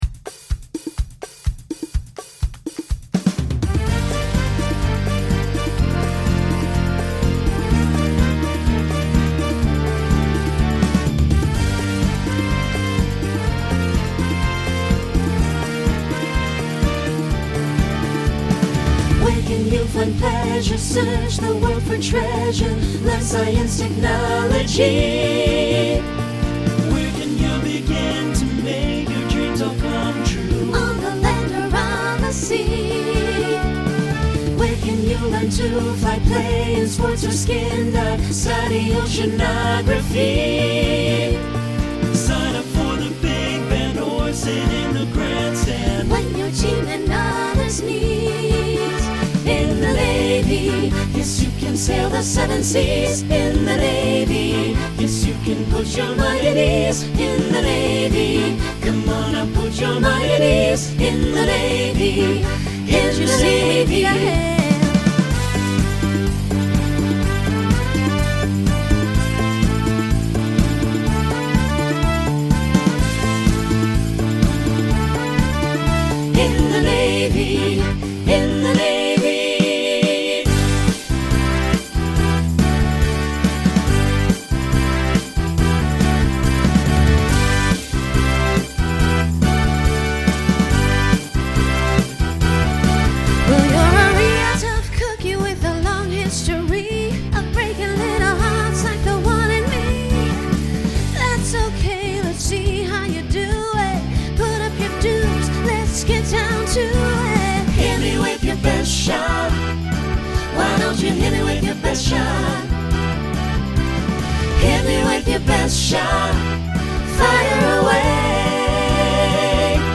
Genre Disco , Rock
Voicing SATB